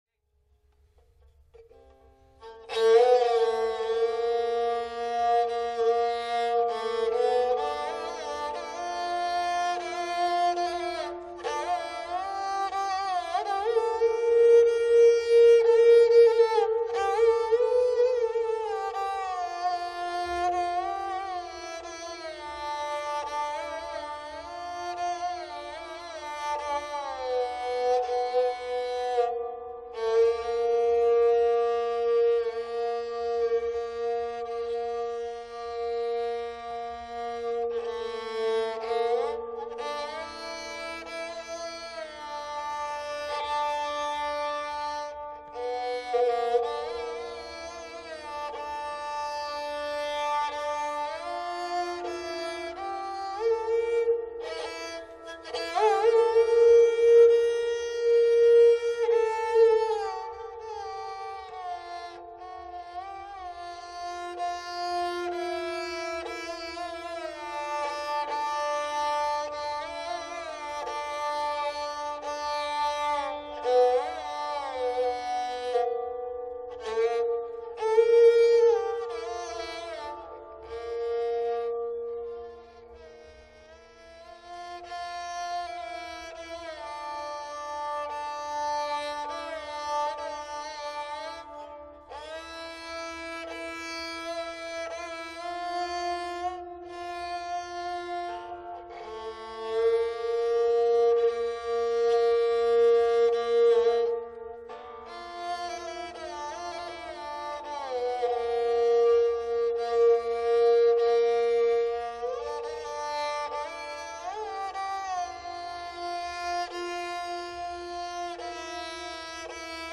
Rishab Dhaivat Komal and Madhayam Teebar. In Aroh Rishab & Dhaivat are absent.
Pakar:  Sa Ga, Pa, Ma(t) Dha(k) Pa, Ma(t) Ga Ma Ga Re(k) Sa
Dilruba :
dilruba_jaitsri.mp3